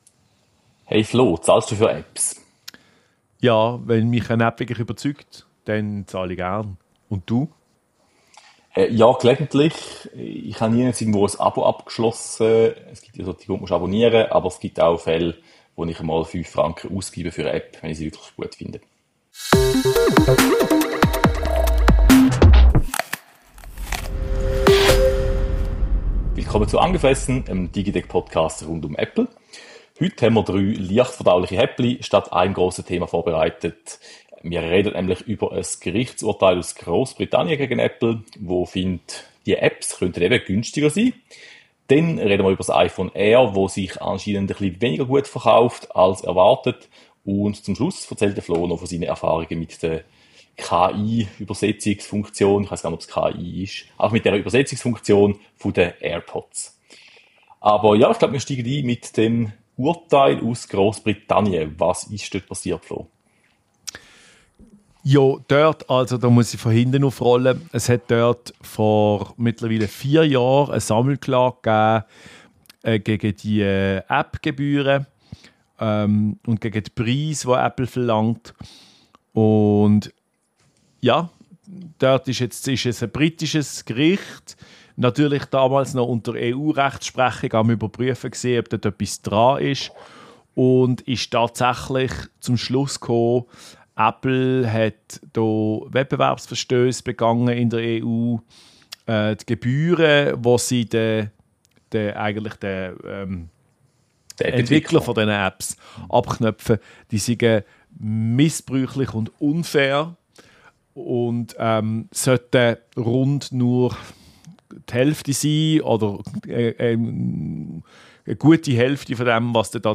Hinweis: Wegen eines technischen Problems mussten wir bei einer Tonspur ein Backup mit schlechterer Audioqualität verwenden.